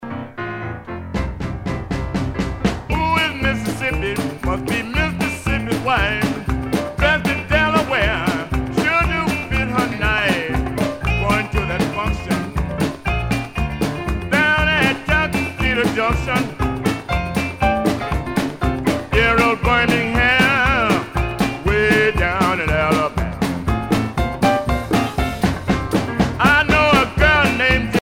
danse : rock